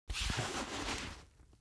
دانلود آهنگ دعوا 2 از افکت صوتی انسان و موجودات زنده
دانلود صدای دعوا 2 از ساعد نیوز با لینک مستقیم و کیفیت بالا
جلوه های صوتی